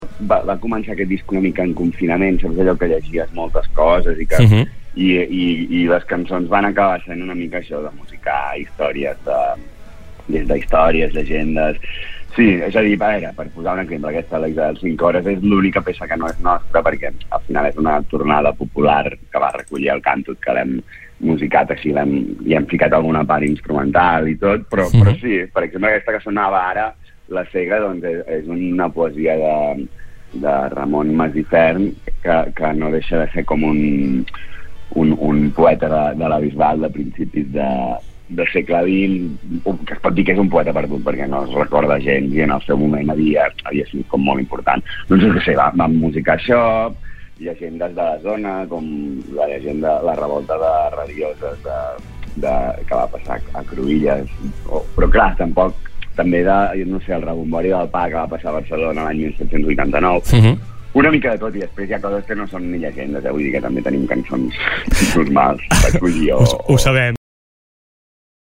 Entrevistes SupermatíMúsica